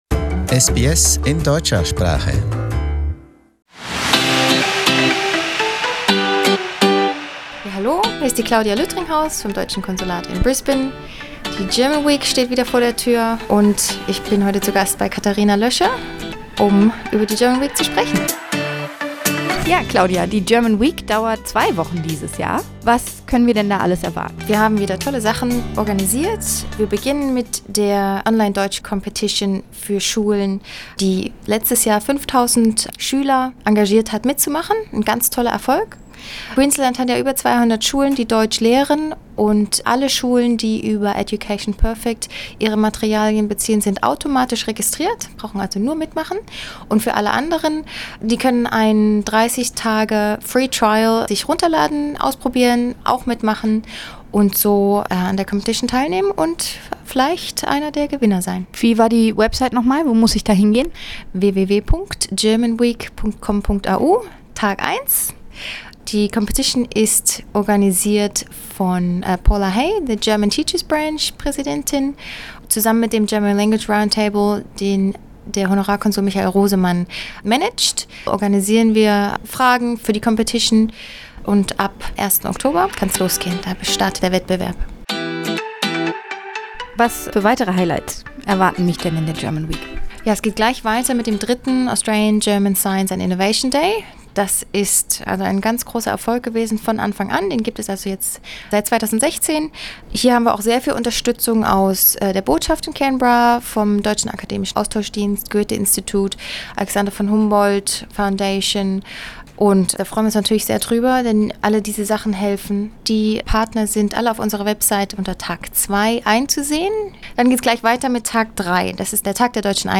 Im Studio